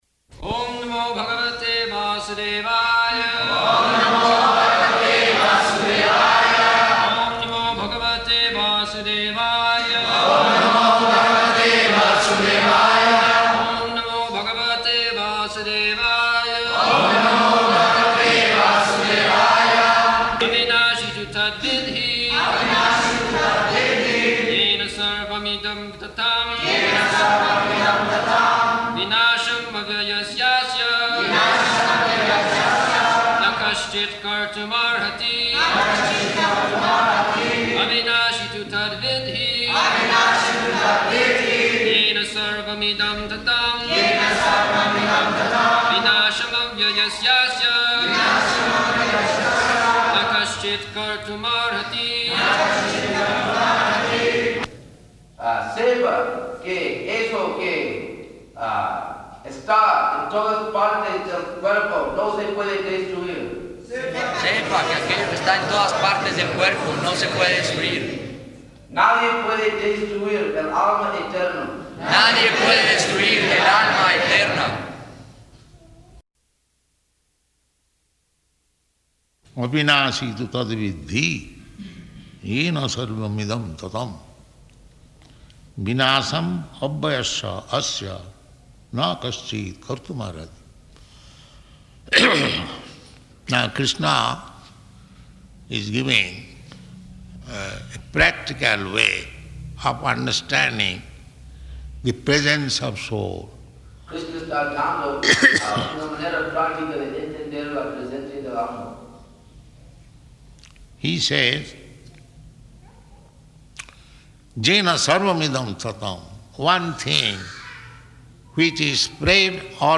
February 17th 1975 Location: Mexico City Audio file
[leads chanting of verse]